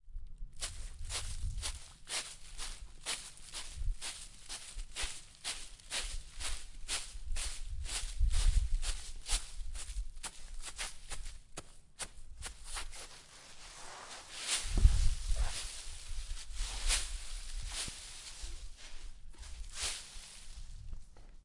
拱叶
描述：走过一堆干树叶。
Tag: 紧缩 秋天 叶捣弄